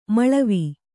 ♪ maḷavi